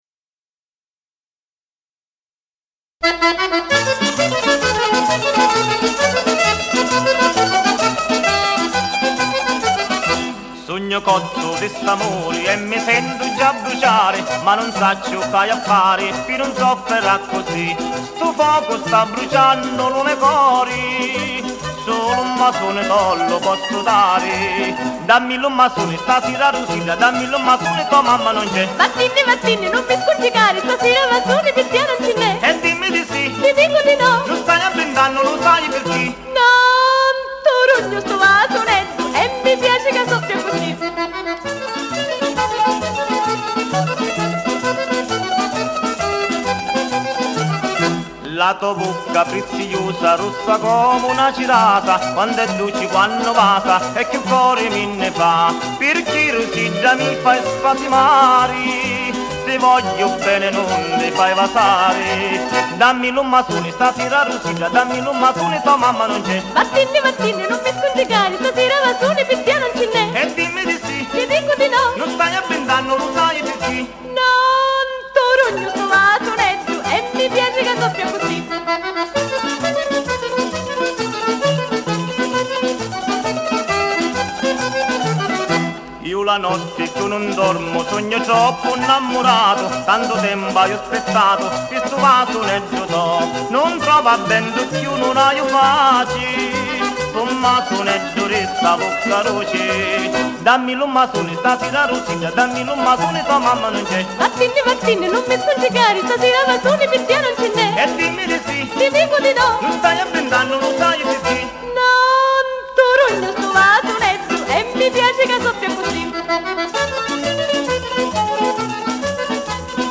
COMPLESSO CARATTERISTICO SICILIANO
ZUFOLO
FISARMONICA